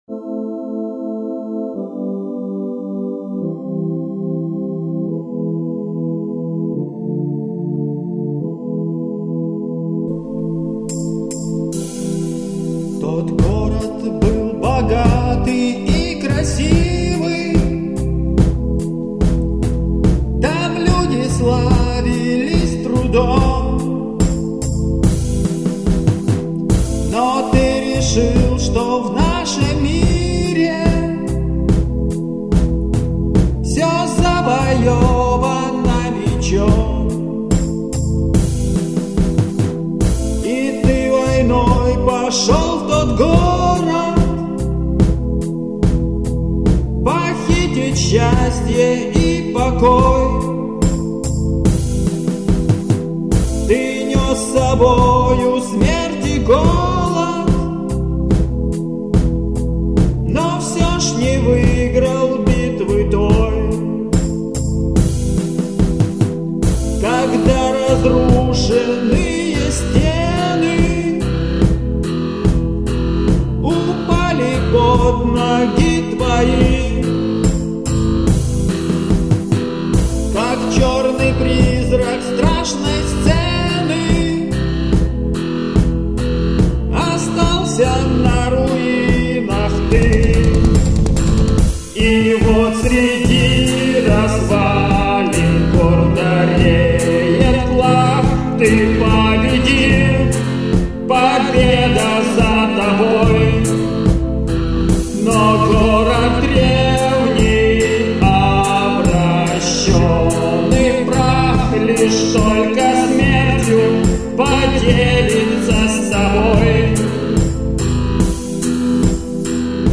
Это песни разных лет (1986-2004).Благодаря программе Fruty Loop Studio в 2005 году выставляю их на Ваш суд.
• Жанр: Рок